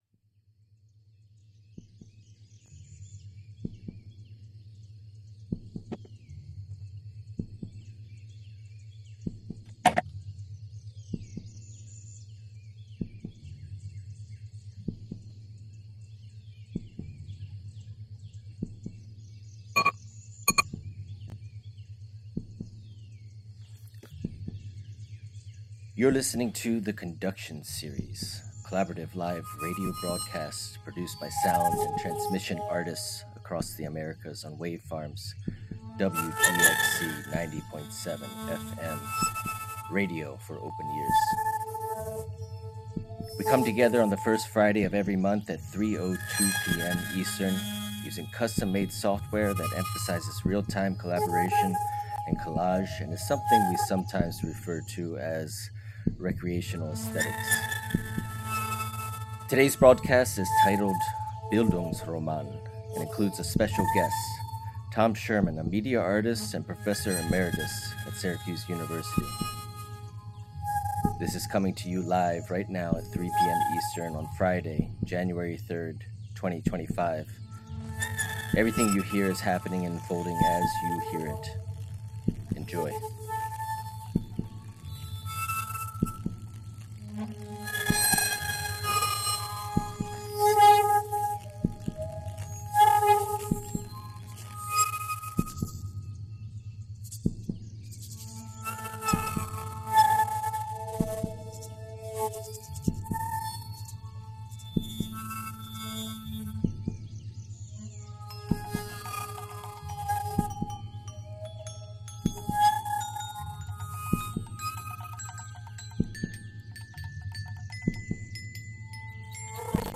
"The Conduction Series" is a collaborative live radio broadcast produced by sound and transmission artists across the Americas on Wave Farm’s WGXC 90.7-FM Radio for Open Ears in New York’s Upper Hudson Valley.